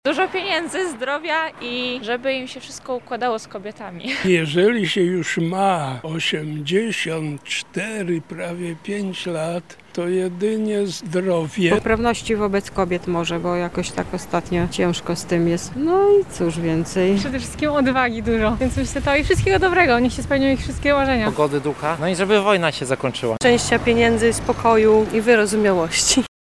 Czego można życzyć mężczyznom? Zapytaliśmy o to mieszkańców Lublina.